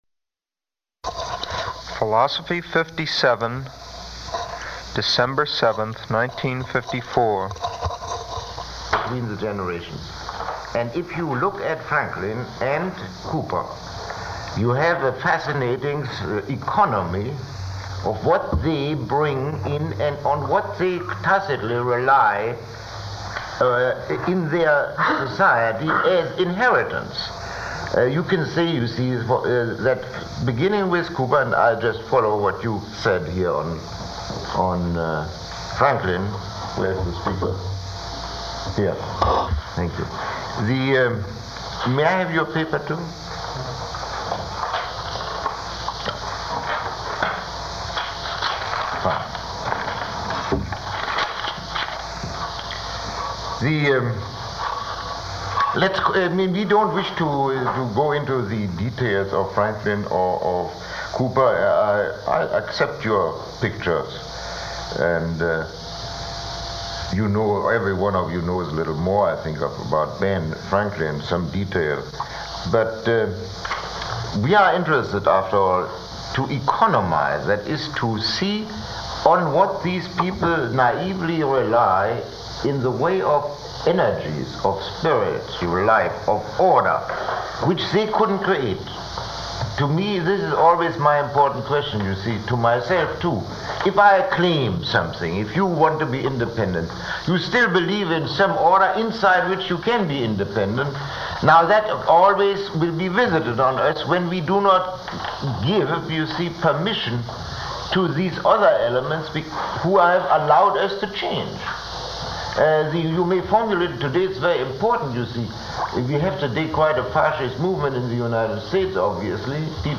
Lecture 10